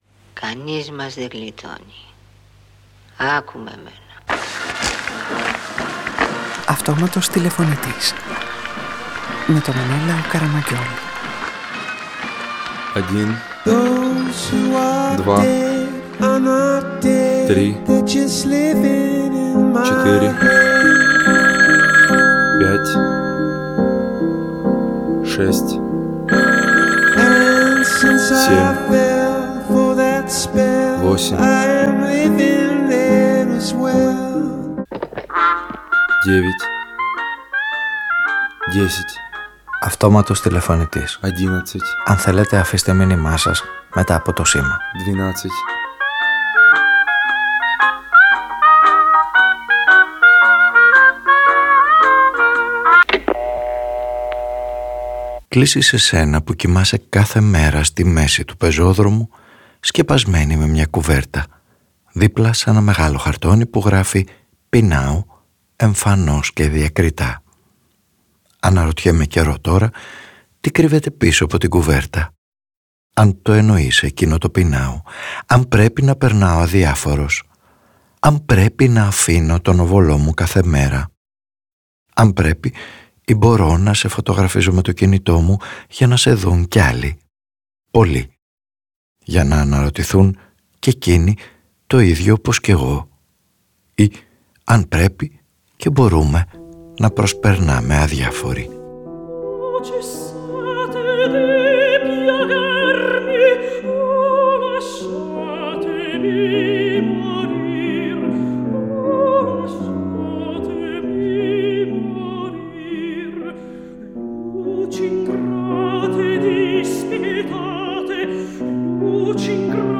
Ο ήρωας της σημερινής ραδιοφωνικής ταινίας πασχίζει να γεφυρώσει το χάσμα θανάτου -γέννησης μέσα από μια εγκυκλοπαίδεια θανάτου που φωτίζει διαφορετικά, δημιουργικά, επινοητικά, ψύχραιμα, παιχνιδιάρικα, αποστασιοποιημένα και με προοπτική ό,τι μας φοβίζει κι ό,τι αποφεύγουμε να σκεφτούμε ό,τι θα μας συμβεί.
Παραγωγή-Παρουσίαση: Μενέλαος Καραμαγγιώλης